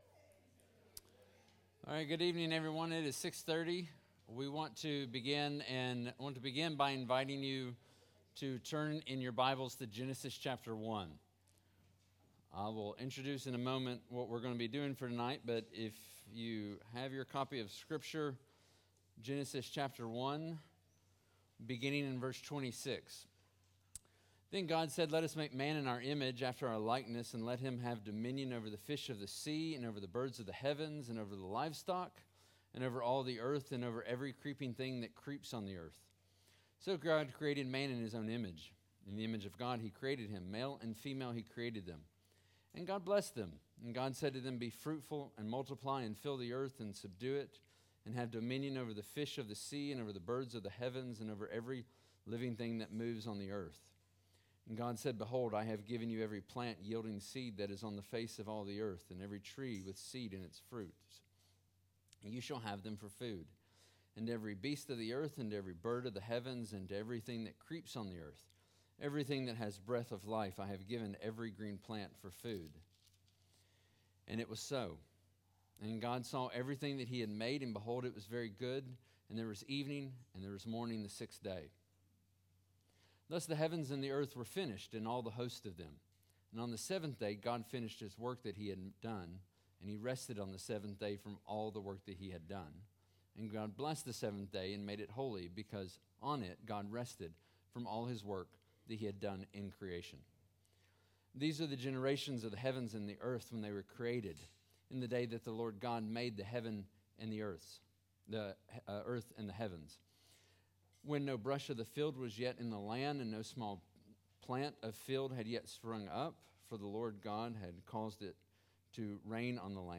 This recording is from a topical Equipping Matters class